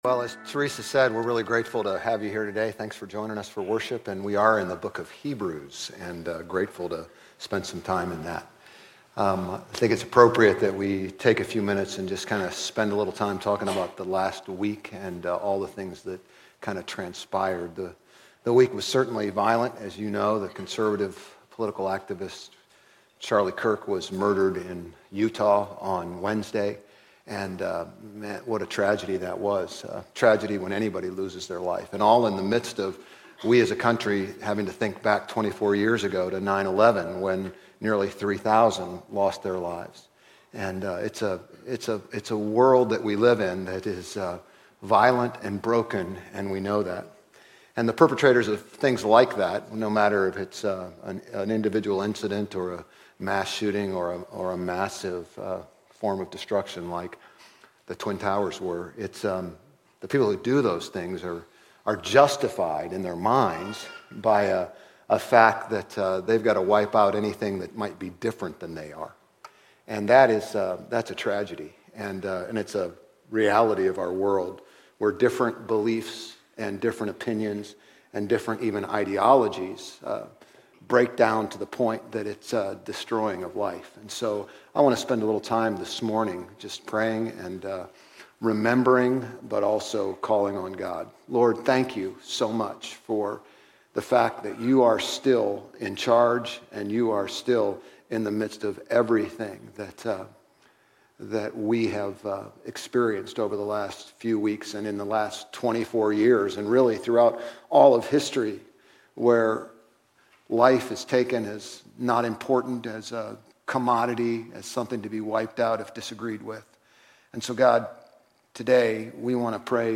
Grace Community Church Old Jacksonville Campus Sermons 9_14 Old Jacksonville Campus Sep 15 2025 | 00:34:50 1x 00:00 / 00:34:50 Subscribe Share RSS Feed Share Link Embed